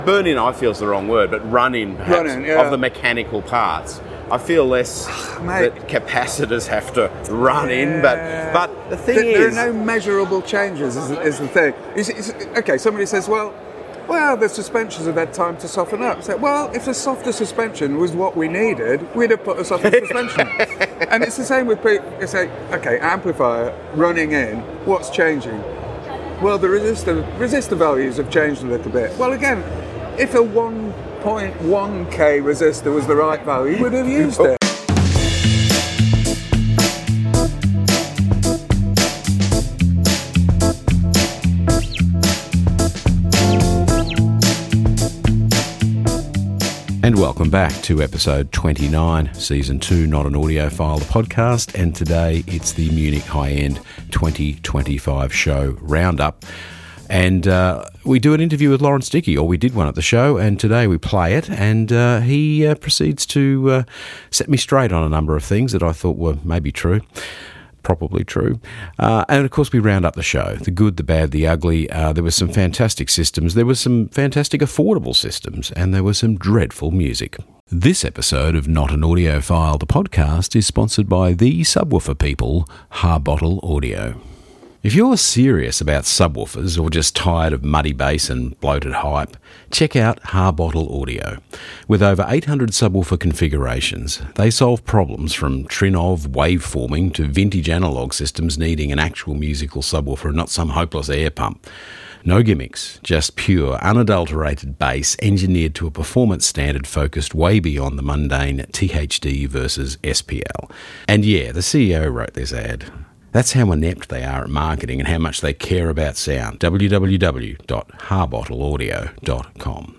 Full interview and 2025 roundup.